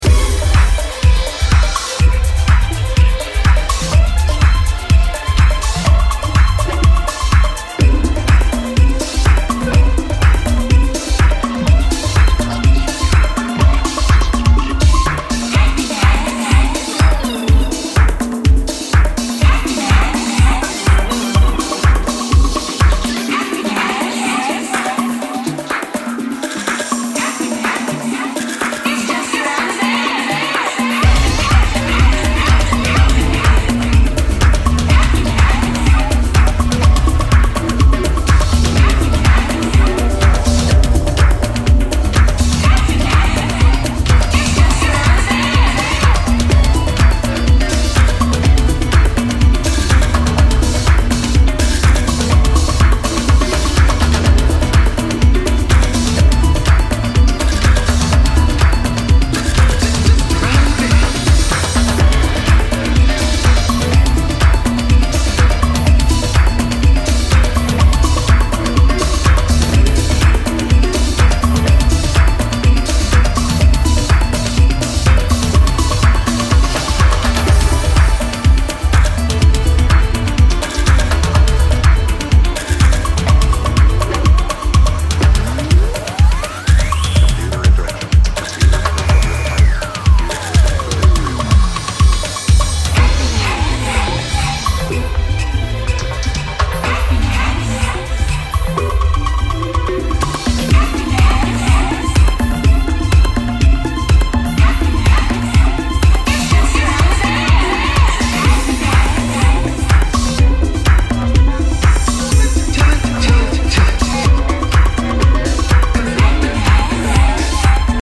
underground dancefloor classic